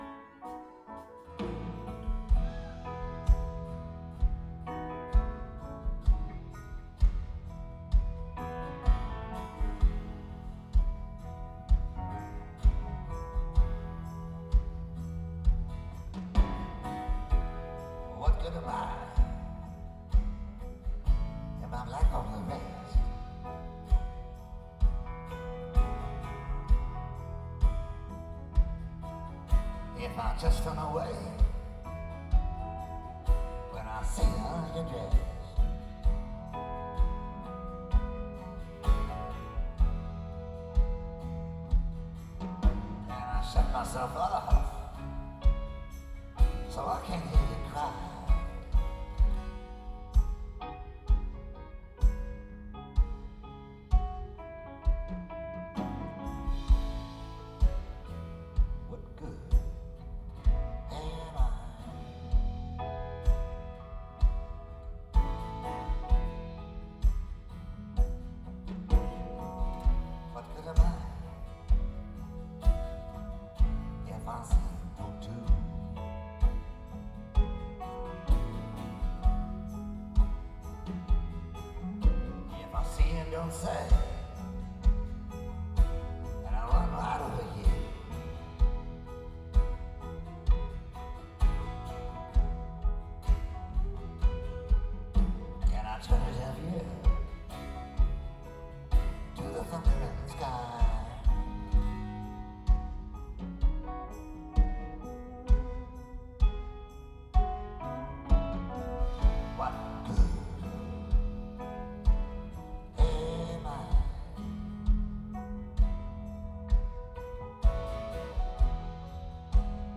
CCH - Hamburg, Germany